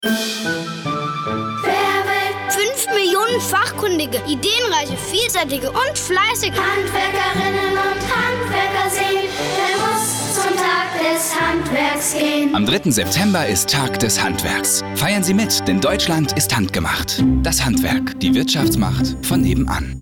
Radio Spot: Hardworking Tradesman ("Fleissige Handwerker")